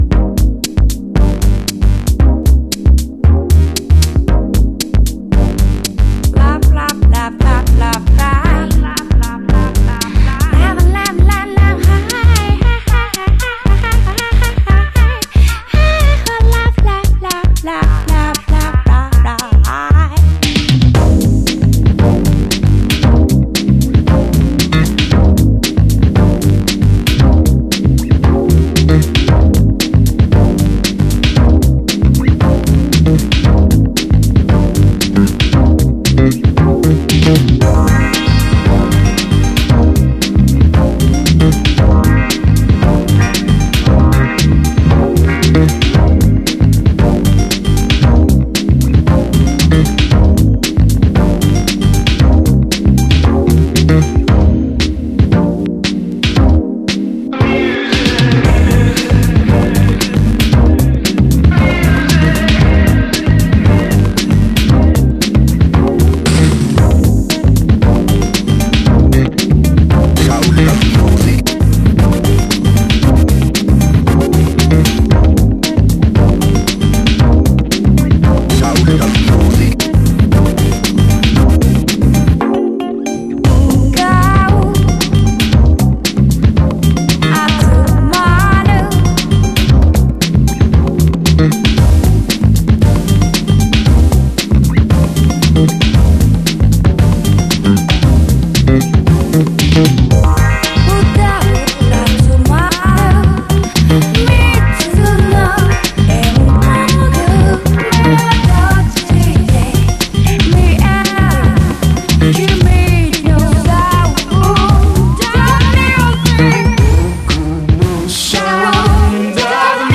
EASY LISTENING / VOCAL / FRENCH / JAPANESE CLUB